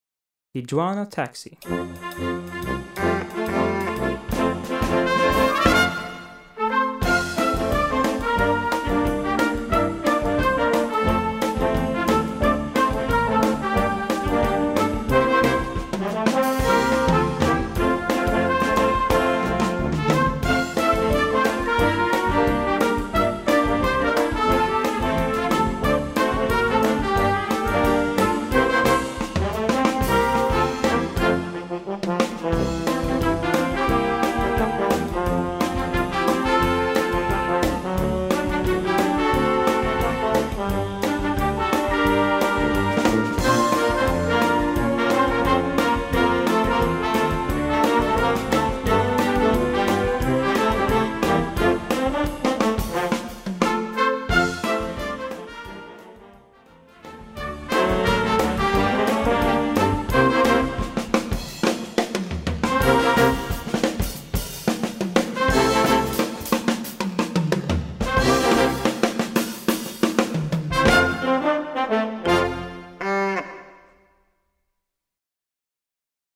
Auch diese Version für Blasorchester macht viel Spass.
Besetzung: Blasorchester